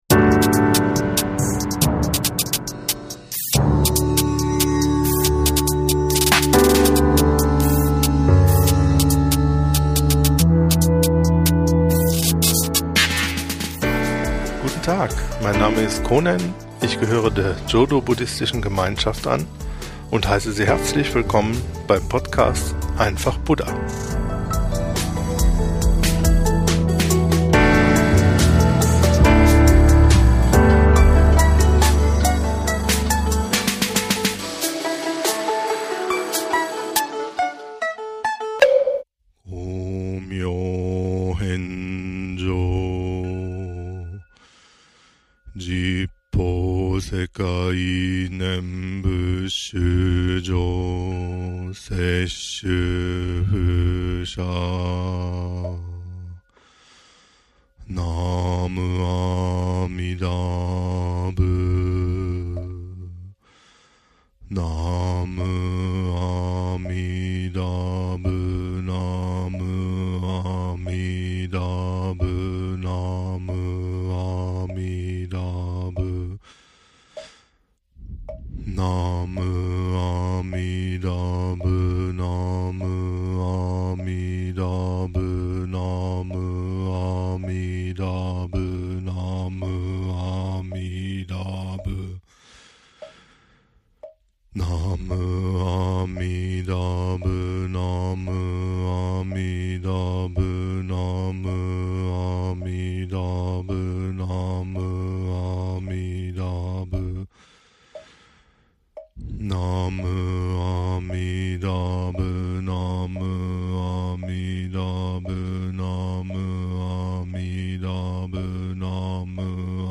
Nenbutsu-Rezitation (langsamere Geschwindigkeit) ~ EINFACH BUDDHA Podcast
Das Tempo der Rezitation spielt dabei keine Rolle; daher diese Folge in langsamer Geschwindigkeit zur Übung. Zur Einleitung wird das "Shoyakumon" aus dem Otsutome gesprochen, zum Abschluss das "So-ekoge" und ein Junen (zehnfaches Nenbutsu)."